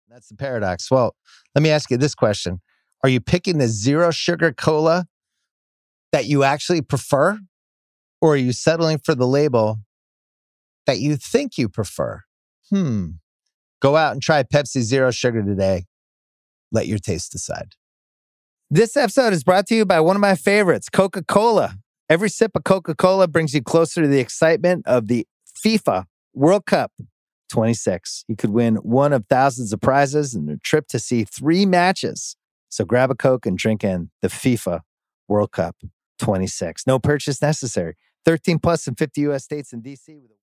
In the latest episode of The Bill Simmons Podcast, as picked up by the “Awful announcing” blog, here’s an astonishing piece of audio - live Bill Simmons, doing an ad read for Pepsi Zero Sugar, then a (different-sounding) DAI host-read ad with Bill Simmons promoting… Coca-Cola.